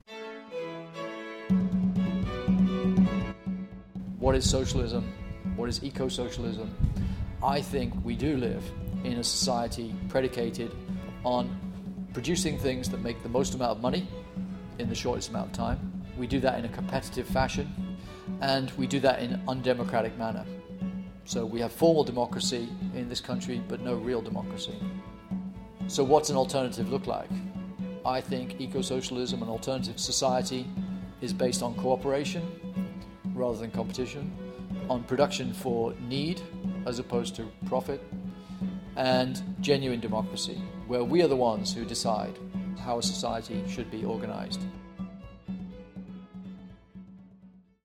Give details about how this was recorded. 80kbps Mono